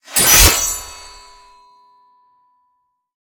BSword3.wav